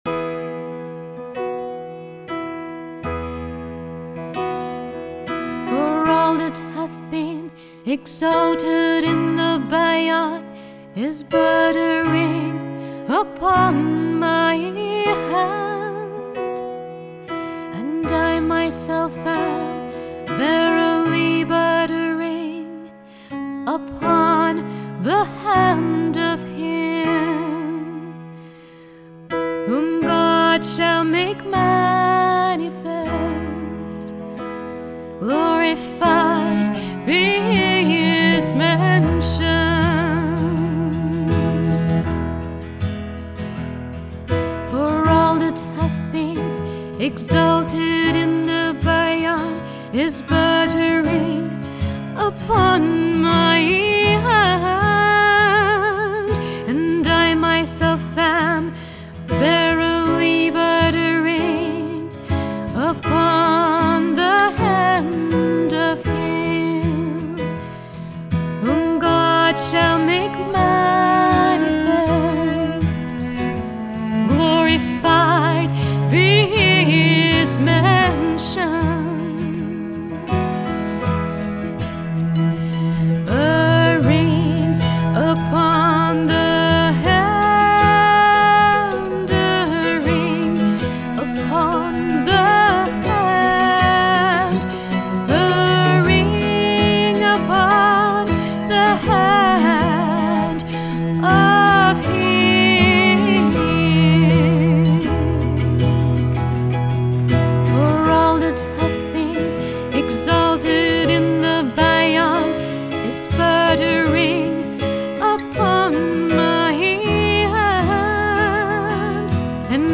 Songs